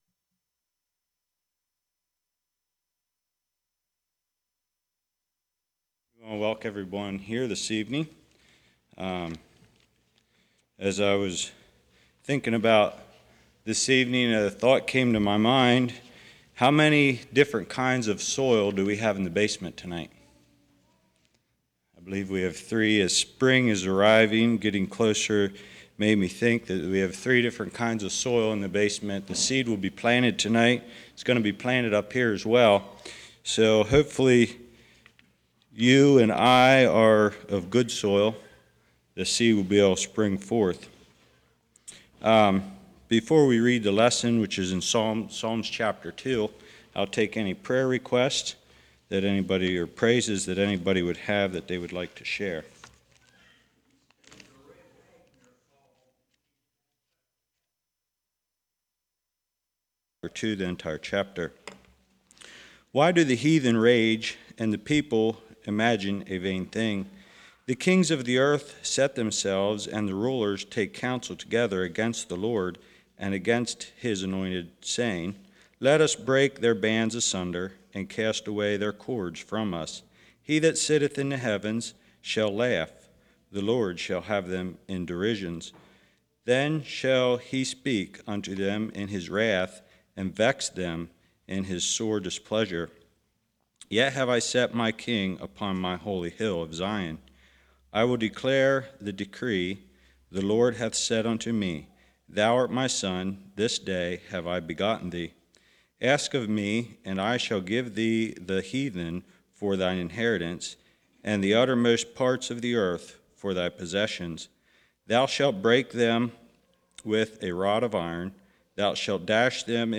Service Type: Winter Bible Study